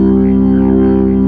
55O-ORG01-C1.wav